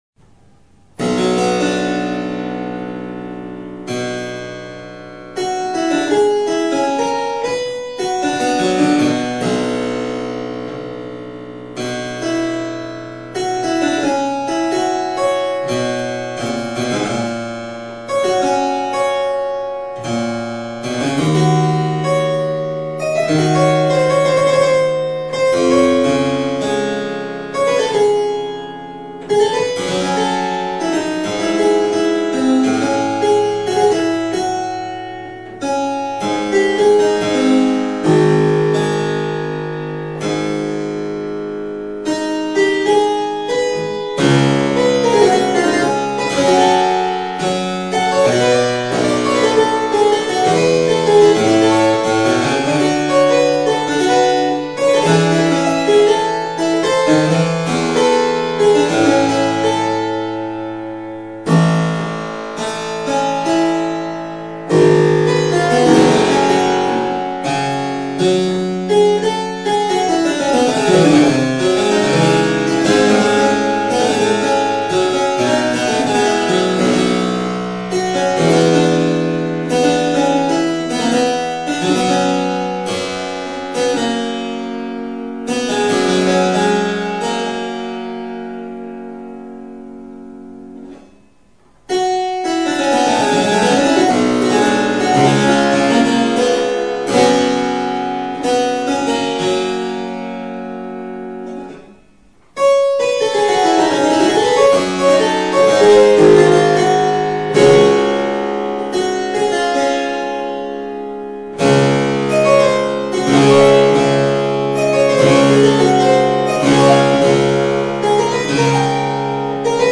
Clavicembalo da Carlo Grimaldi